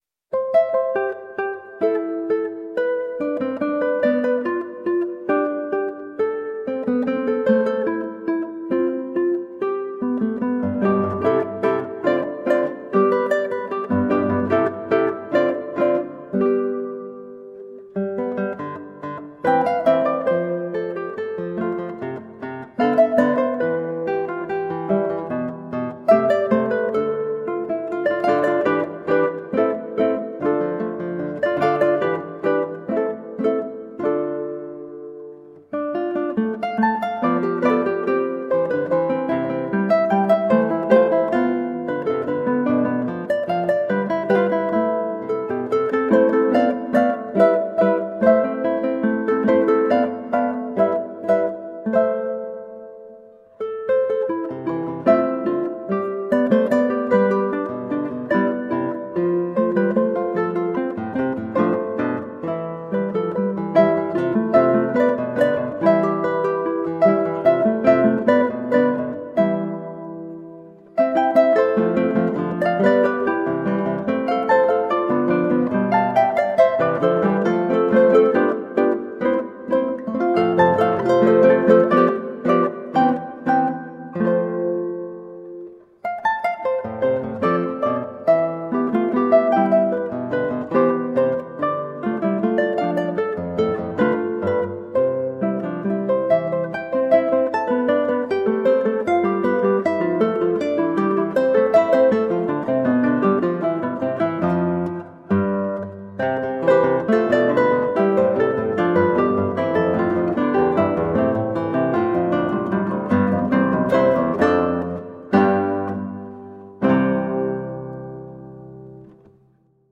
Colorful classical guitar.
Classical Guitar